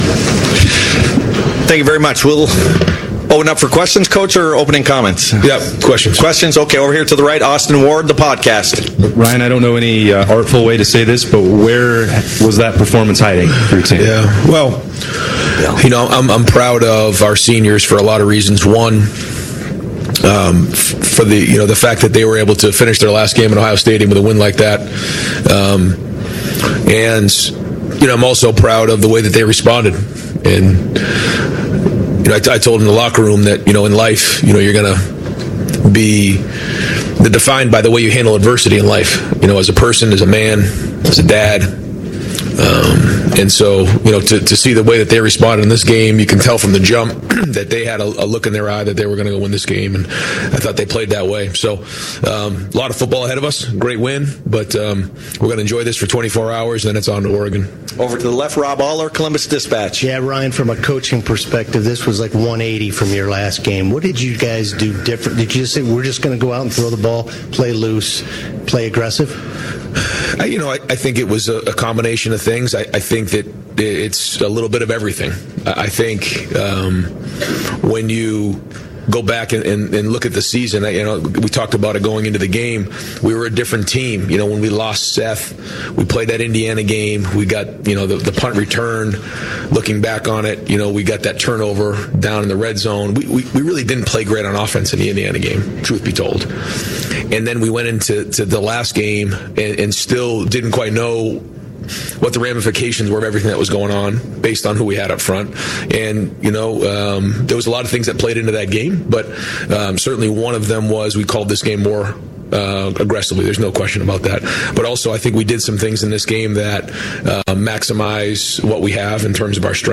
Ryan Day Press Conference after 42-17 #8 Ohio State win over #9 Tennessee in CFP First Round